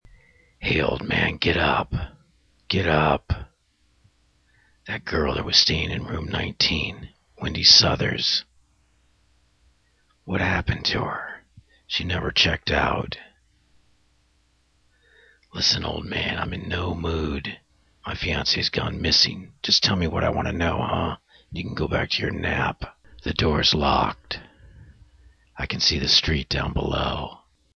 Voice 1
Took me 2 tries to understand the dialogue in voice 1 but love the raspness and implied emotion.
Voice 2 is good, clear, strong, but Voice 1 has an undertone of menace.
The perfect horror game voice, great inflection and tone.
I had a difficult time hearing him (loudness wise) but he sounded more compelling.